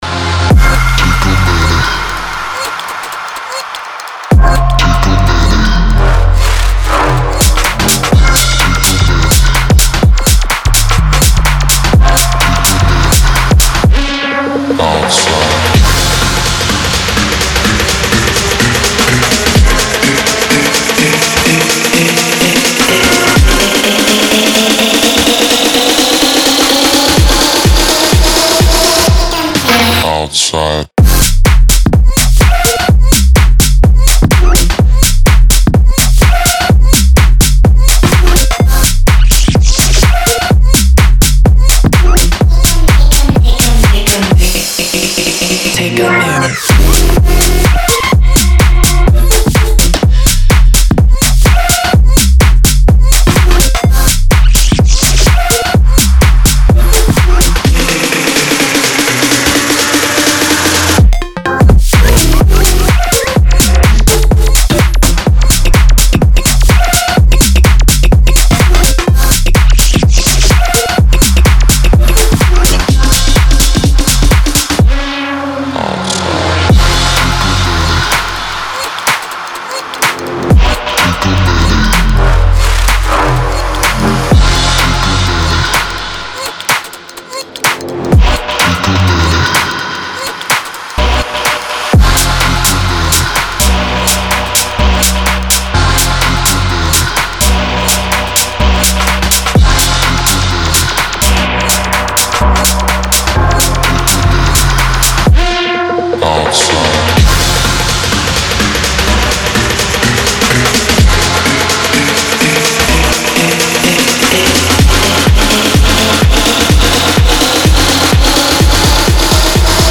Future House, Dark, Angry, Epic, Gloomy, Restless, Energetic